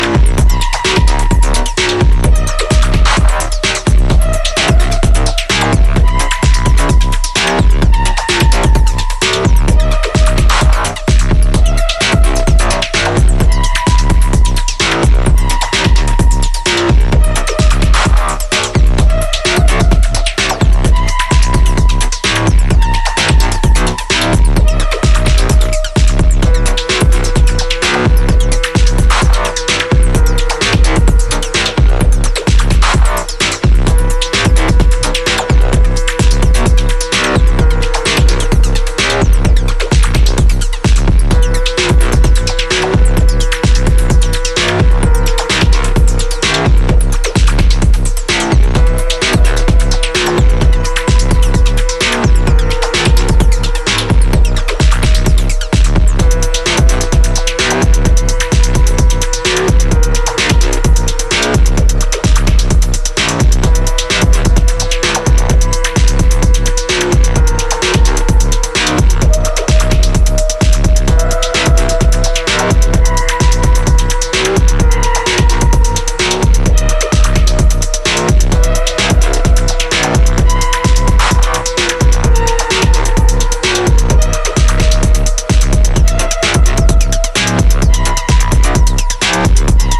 Raw and energetic new forms.